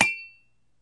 描述：一个小的金属喷雾可以与另一个物体碰撞
标签： 金属 铅笔 可以平 击中
声道立体声